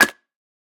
Minecraft Version Minecraft Version snapshot Latest Release | Latest Snapshot snapshot / assets / minecraft / sounds / block / mud_bricks / step5.ogg Compare With Compare With Latest Release | Latest Snapshot
step5.ogg